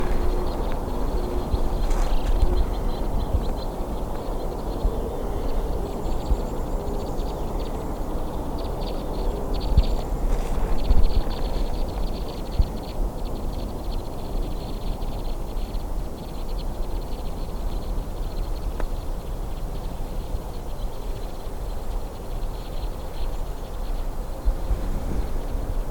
WHITE-WINGED CROSSBILL
They could easily be located by their calls;